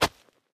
Terrarum/assets/mods/basegame/audio/effects/steps/SAND_4.ogg at b2ea61aa4dc8936b2e4e6776bca8aa86958be45d
SAND_4.ogg